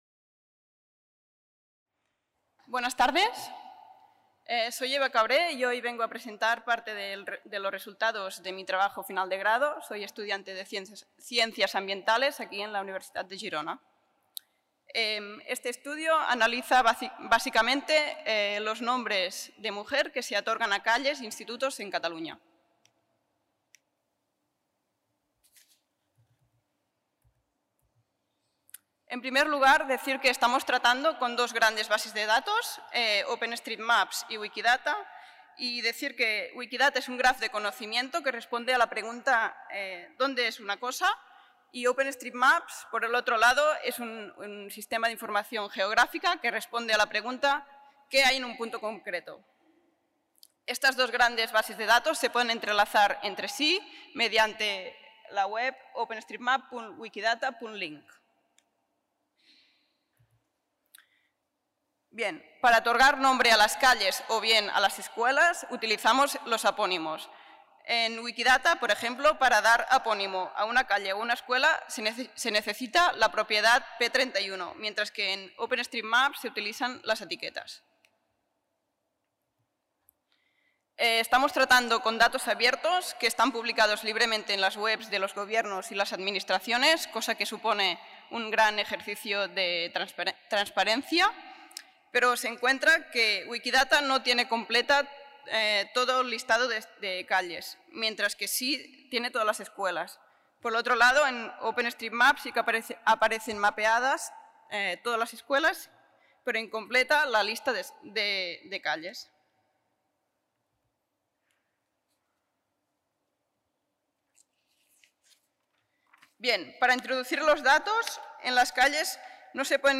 Xerrada sobre la presència de les dones en la toponímia dels carrers i escoles de Catalunya. Es fa visible amb programes d'informació geogràfica la distribució dels carrers segons els noms masculins o femenins